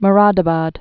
(mə-rädə-bäd, môrə-də-băd)